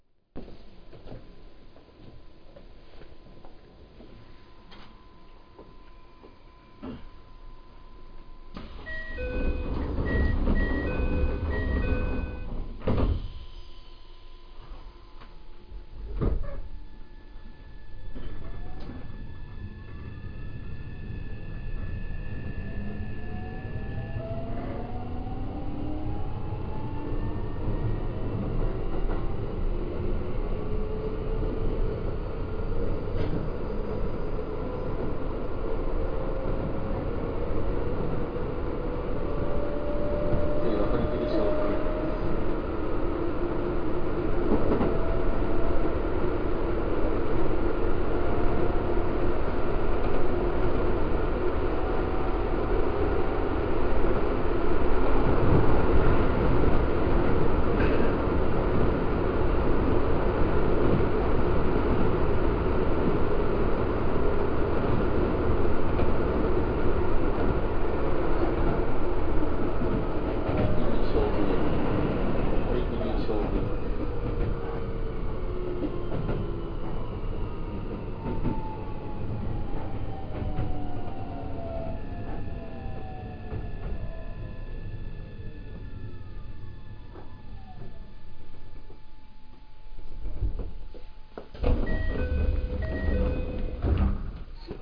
・3000形3003F走行音
【京成本線】お花茶屋〜堀切菖蒲園（1分28秒：743KB）
3003Fは登場当初は他の編成同様の東洋IGBTでしたが、後に東洋SiCを試験的に採用しており、他の編成と比べて異彩を放っています。とはいえ、このモーター音自体は3100形には引き継がれていないようです。